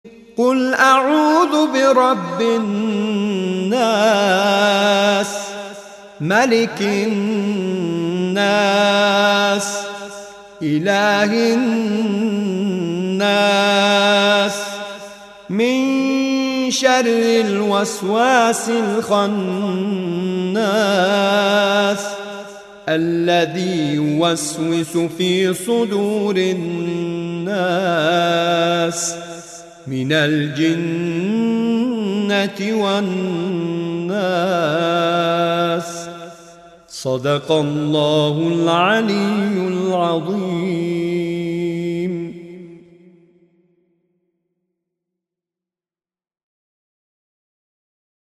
سوره مبارکه ناس آیات 1 تا 6/ نام دستگاه موسیقی: بیات